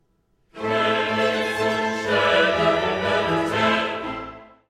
Sanctus” opens as a stately Type I chorus, which continues for the first statement of